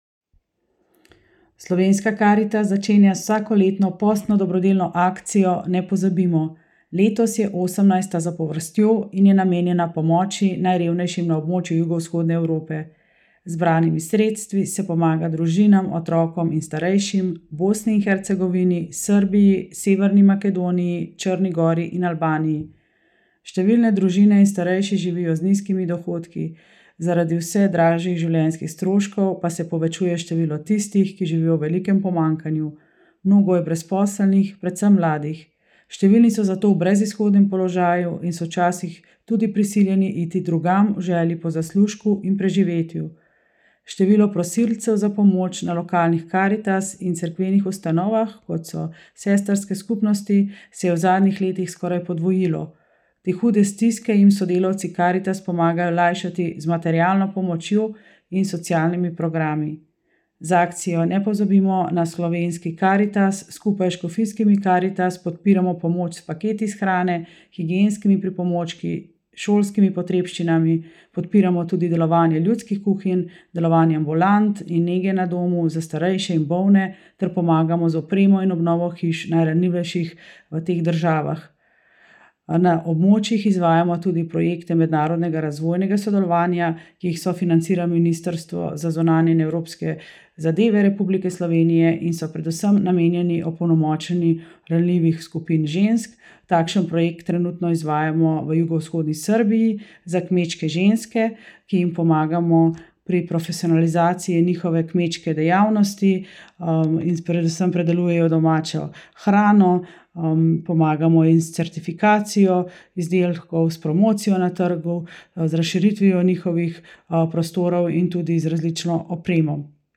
AUDIO izjava